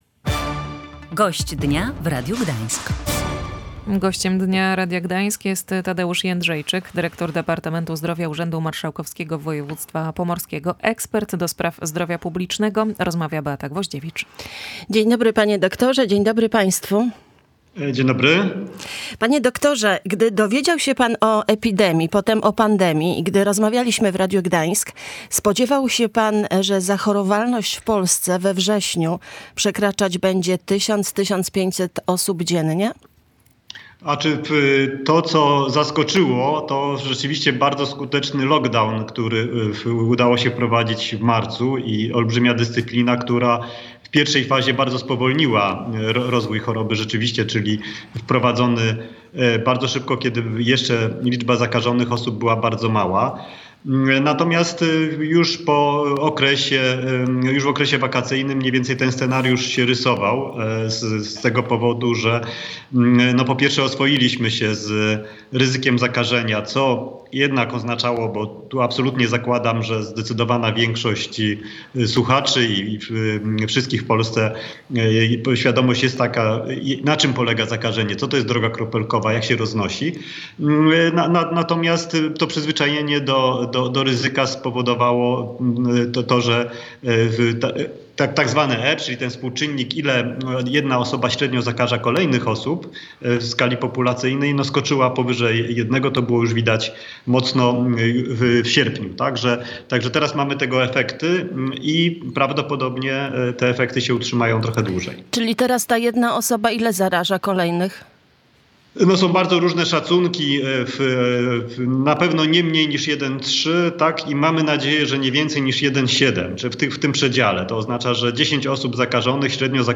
Ekspert ds. zdrowia publicznego o rozprzestrzenianiu się koronawirusa: 10 chorych osób zaraża do 17 kolejnych